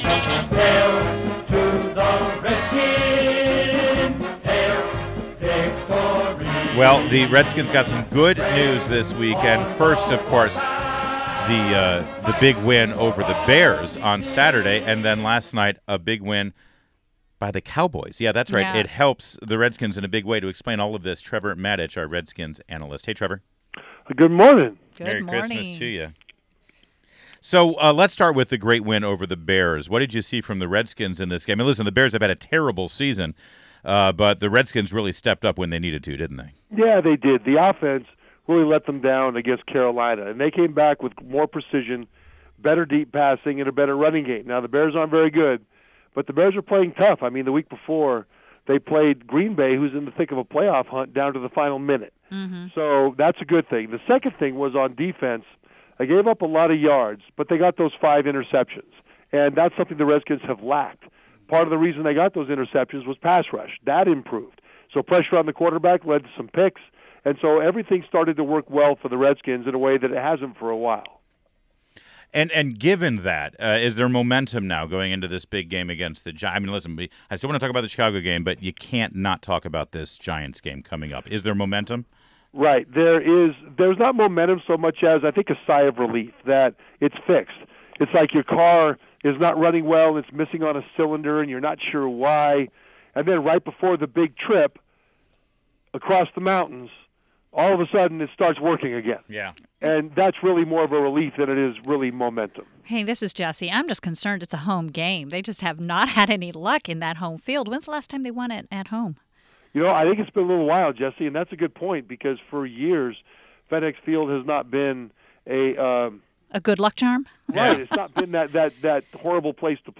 WMAL Interview - Trevor Matich 12.27.16